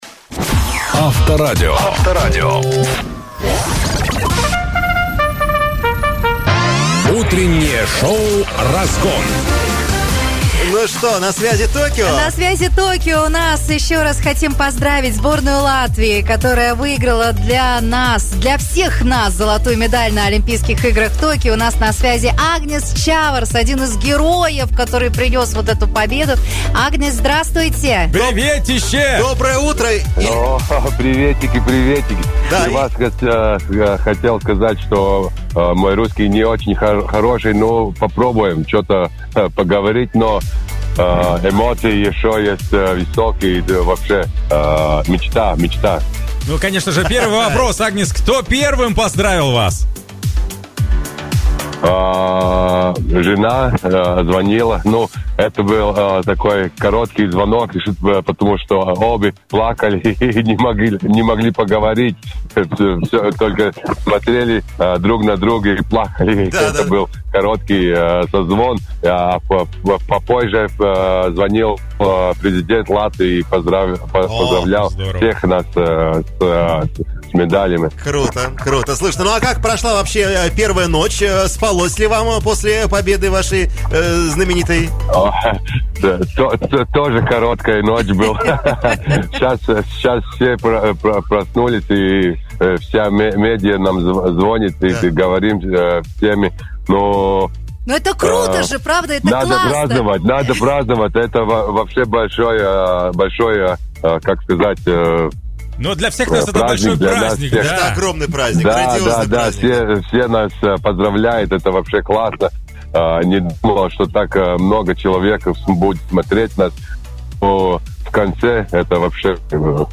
Победитель Олимпийских игр в Токио в составе сборной Латвии по баскетболу 3×3 Агнис Чаварс рассказал в интервью «Авторадио» об эмоциях, которые он испытывает, завоевав олимпийское золото, поддержке болельщиков и многочисленных поздравлениях, жизни в Олимпийской деревне в условиях коронавирусных ограничений, картонных кроватях для олимпийцев и гонораре за победу на Олимпиаде.